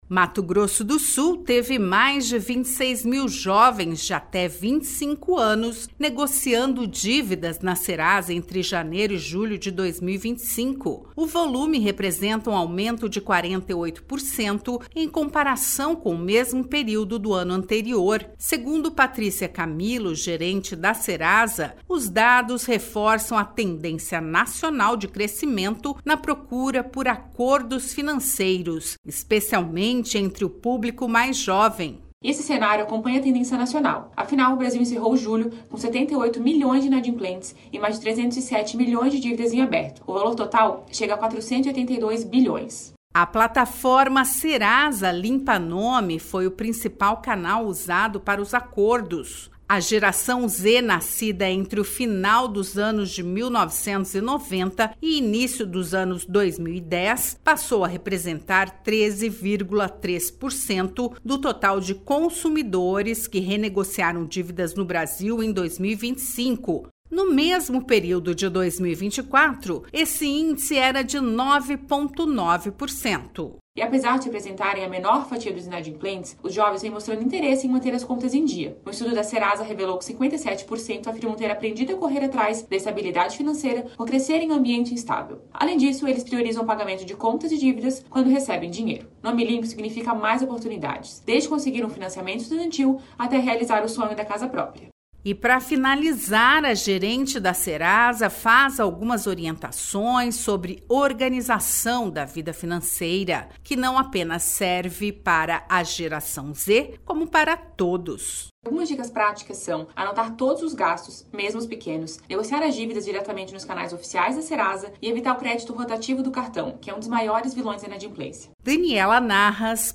A repórter